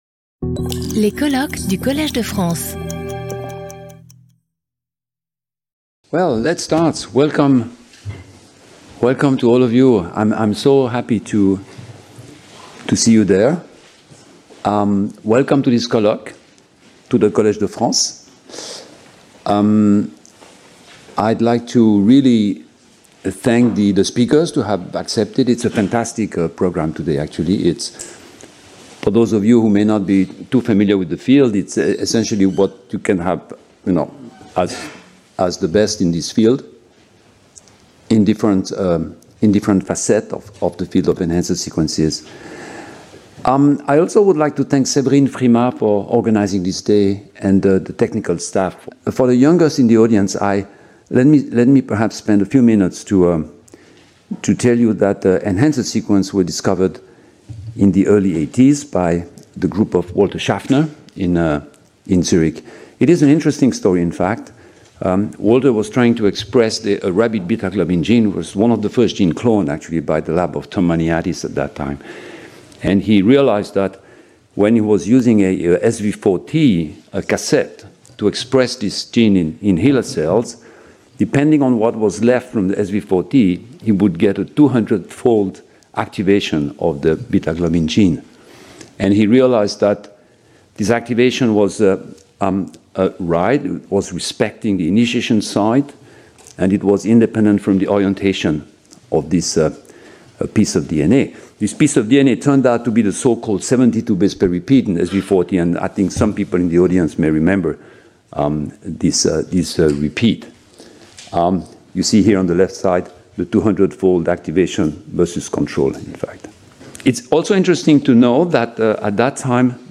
Speaker(s) Denis Duboule Professor at the Collège de France
Symposium